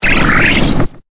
Cri de Clic dans Pokémon Épée et Bouclier.